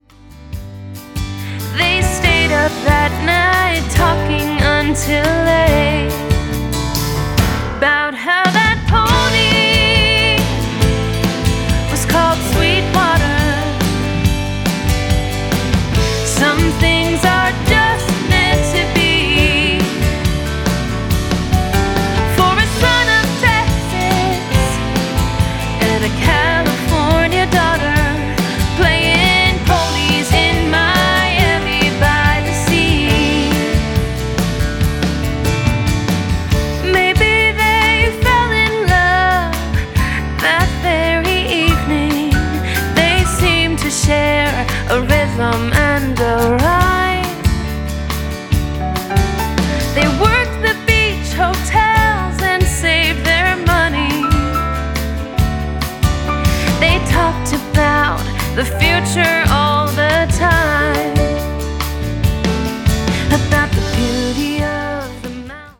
backed once again by the top Nashville studio musicians.
Or the playful story song